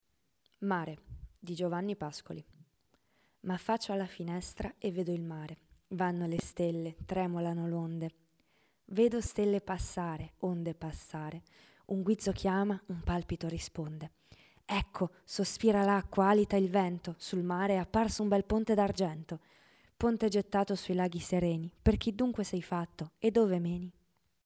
Fichier audio du poème utilisé dans le parcours Le poème mis en voix FRA 3-4
L1_34_P_poeme_RNS4_mare.mp3